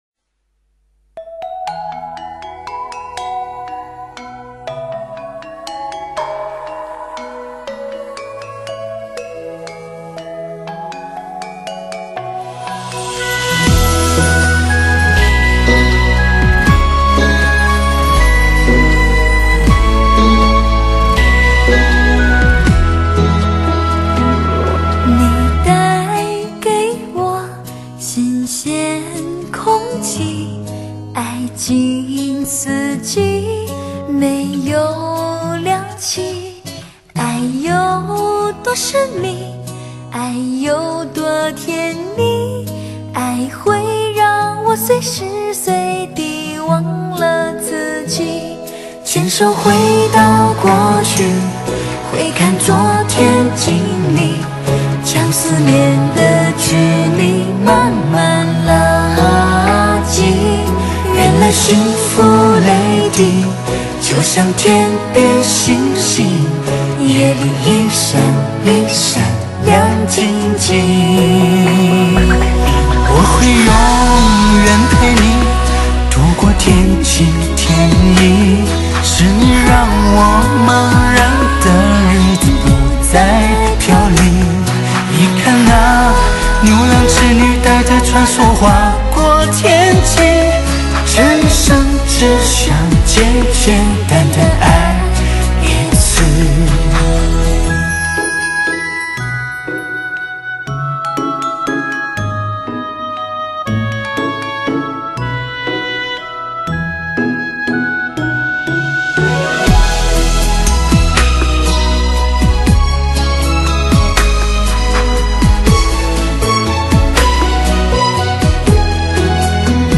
汽车黑胶 SRS+360环绕立体声
量身打造车载音响空间3D环绕HI-FI真品质黑胶 高保真现场震撼